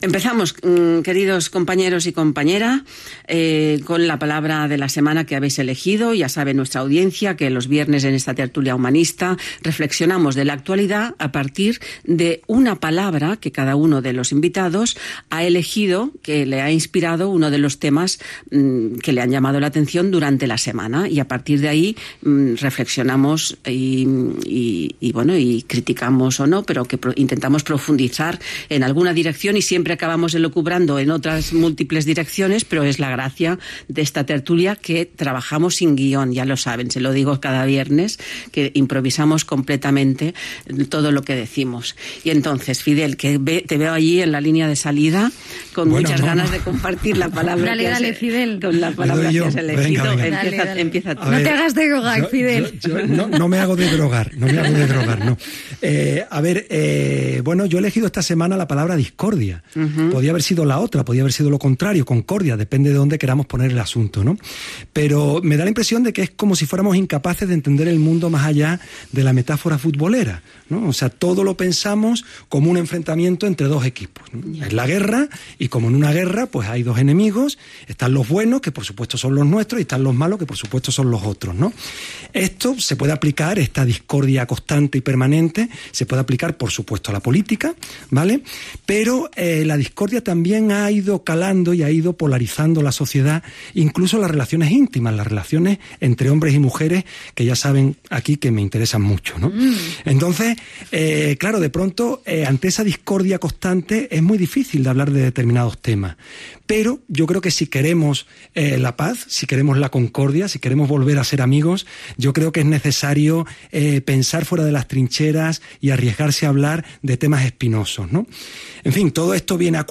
Fragment de la tertúlia humanista del programa.